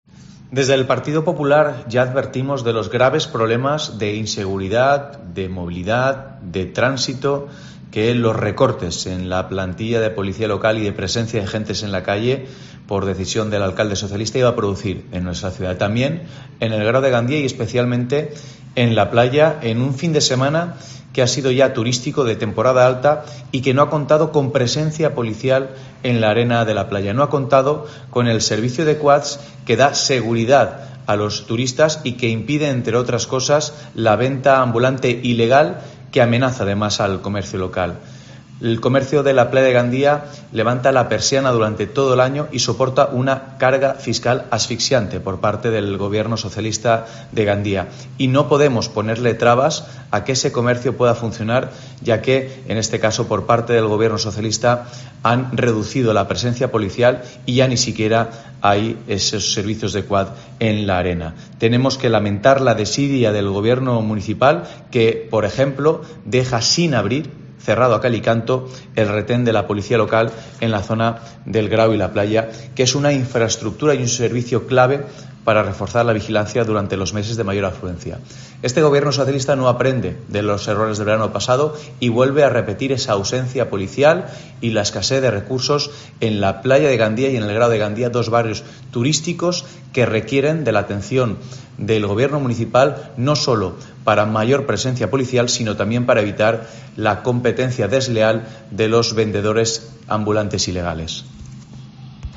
PP, Víctor Soler (audio)